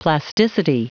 Prononciation du mot plasticity en anglais (fichier audio)
Prononciation du mot : plasticity